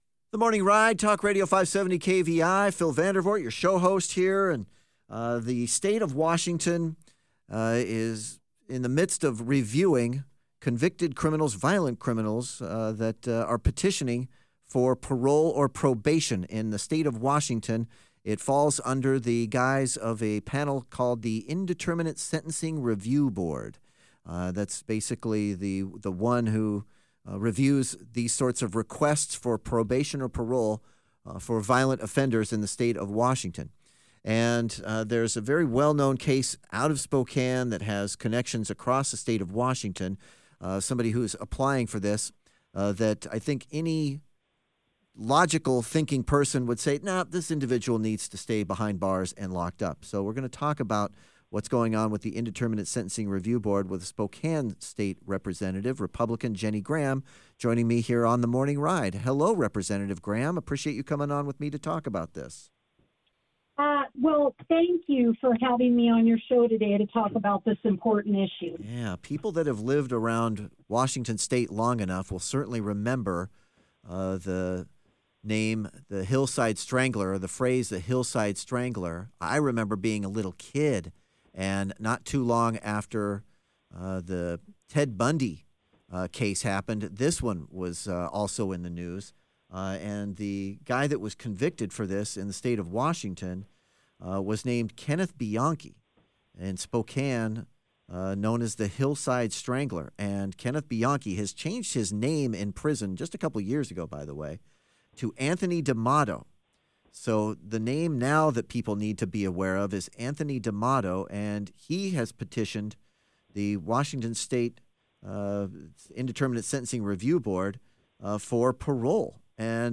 Washington State representative Jenny Graham (R – Spokane) joins The Morning Ride to share more details about how we can help keep this killer behind bars.